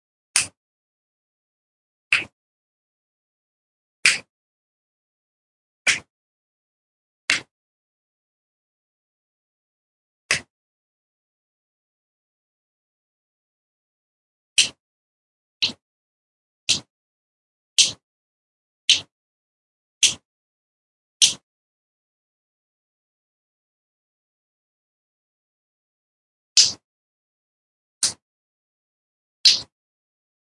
人类的假声" Snap
描述：手指大声啪的一声
Tag: 裂纹 手指 卡扣